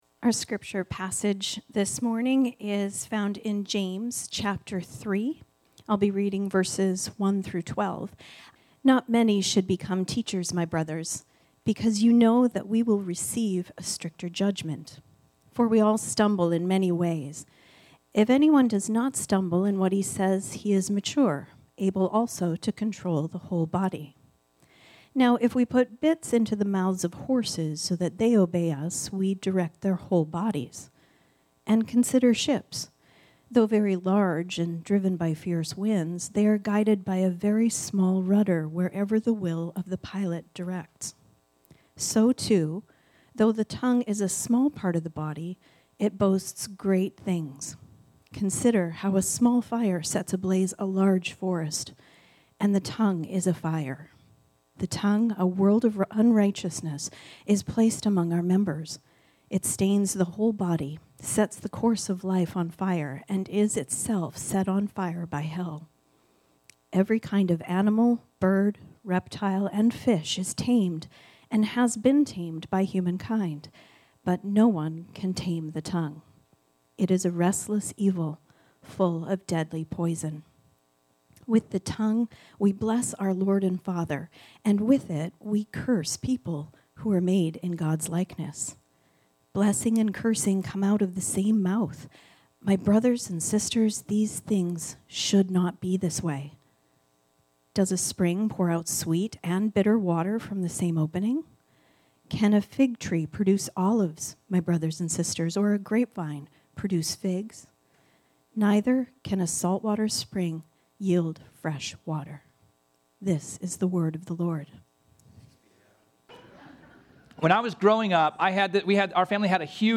This sermon was originally preached on Sunday, November 2, 2025.